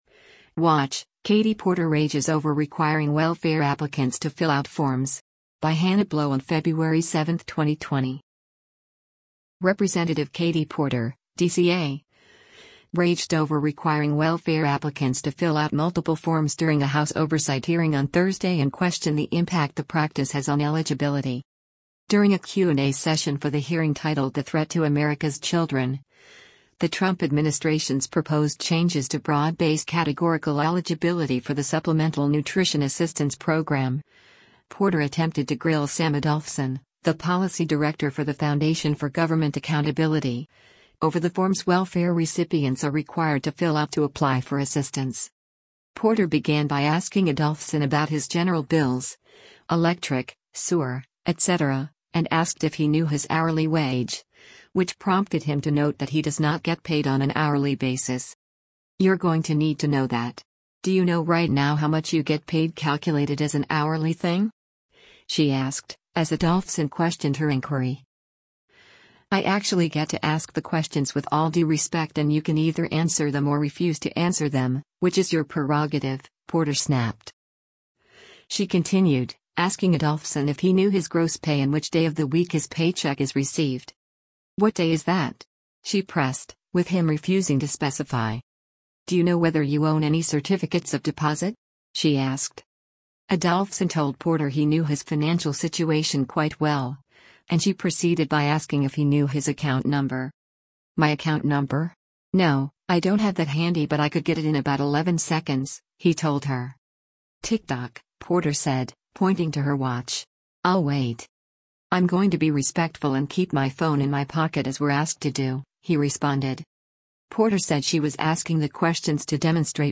Video Source: House Oversight Committee
Rep. Katie Porter (D-CA) raged over requiring welfare applicants to fill out multiple forms during a House Oversight hearing on Thursday and questioned the impact the practice has on eligibility.